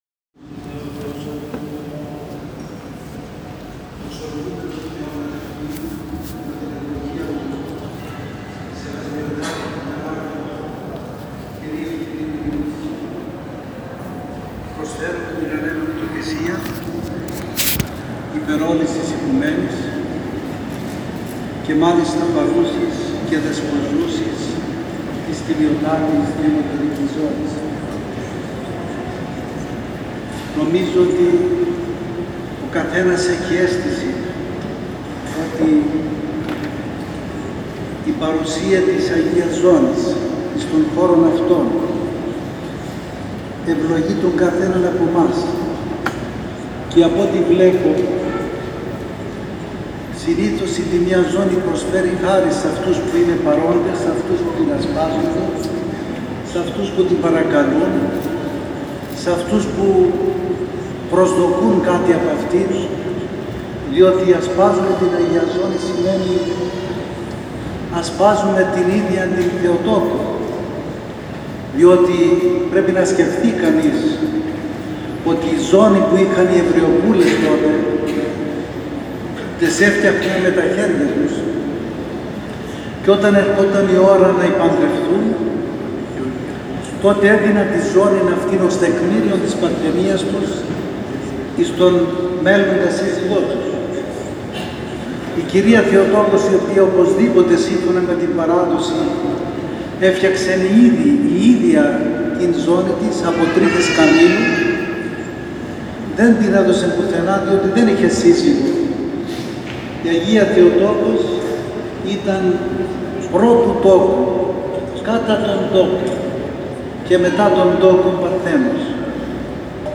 Θεία Λειτουργία για την έλευση και παραμονή της Τιμίας Ζώνης της Υπεραγίας Θεοτόκου τελέστηκε σήμερα, Παρασκευή 28 Ιουνίου 2019 στον Καθεδρικό Ναό του Αποστόλου Παύλου στην πόλη της Κορίνθου.